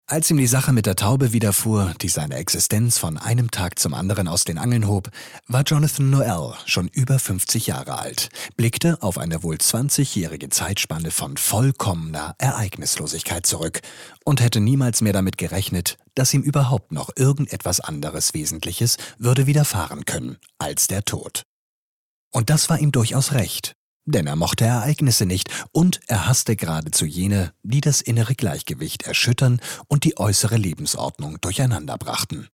Comment (Kommentar)